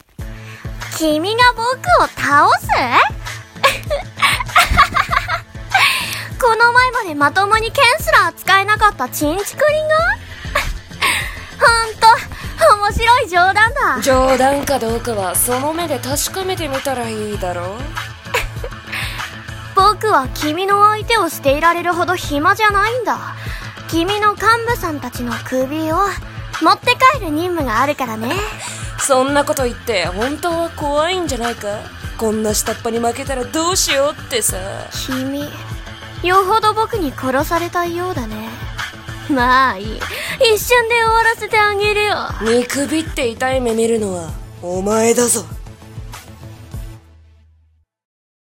【声劇】敵への挑発【掛け合い】